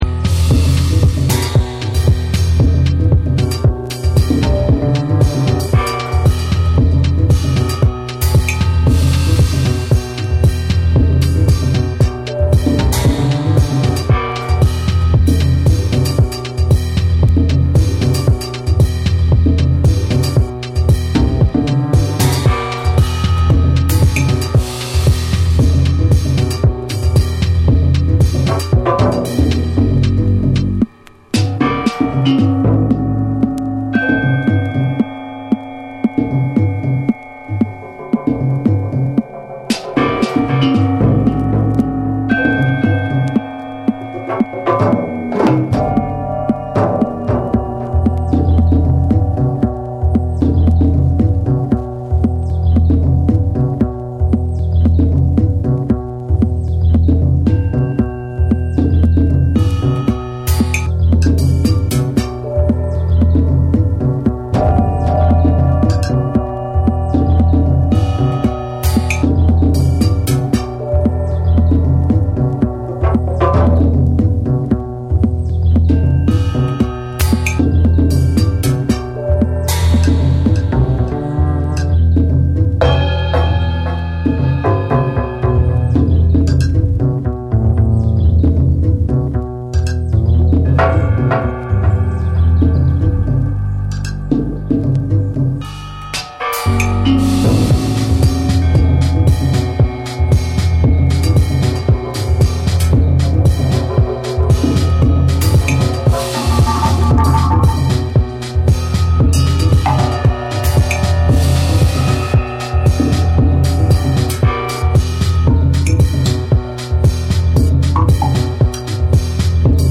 ジャズ・グルーヴとエレクトロニクスが融合した
TECHNO & HOUSE / ORGANIC GROOVE